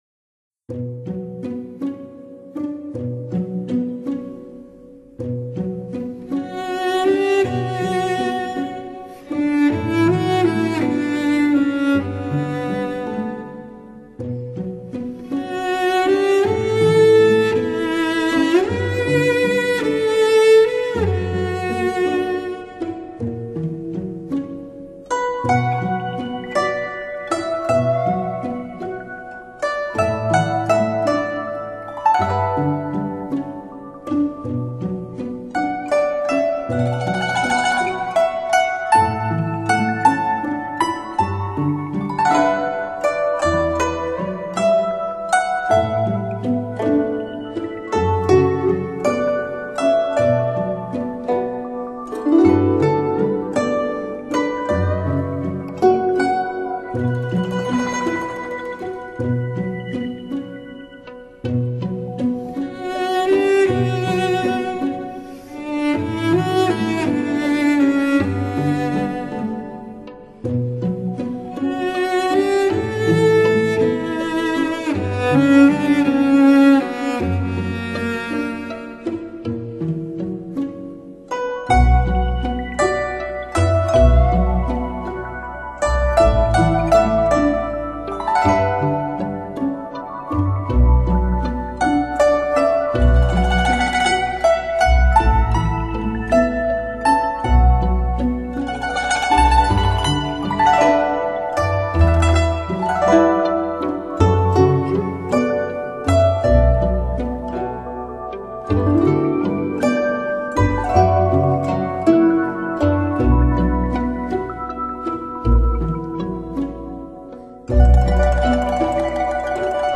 音乐类型：古筝